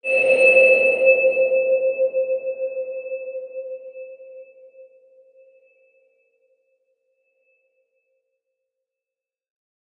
X_BasicBells-C3-mf.wav